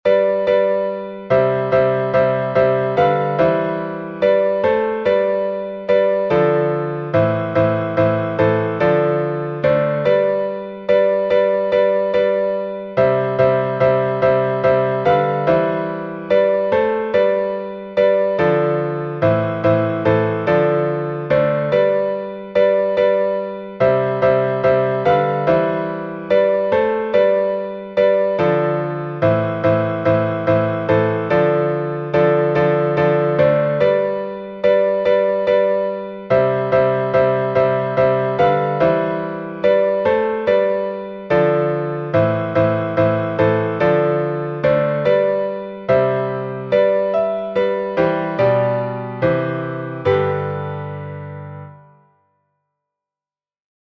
Глас 3